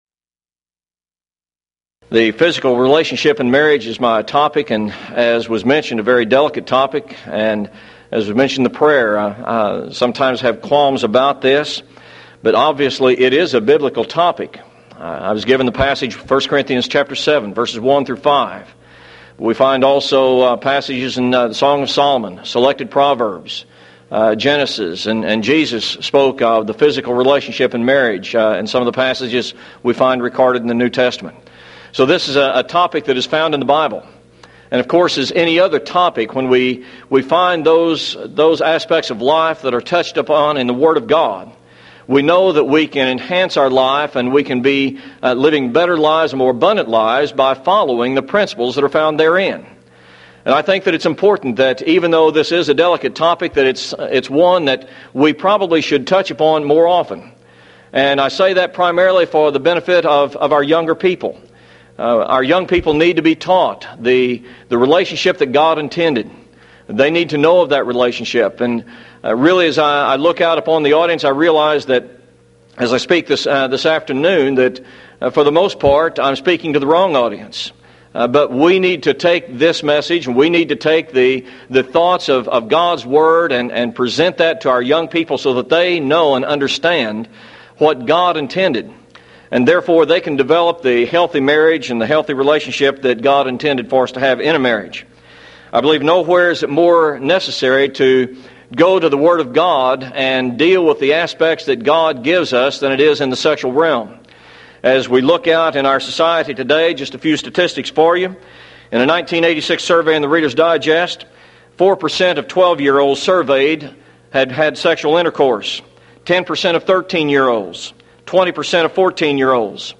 Event: 1993 Mid-West Lectures
lecture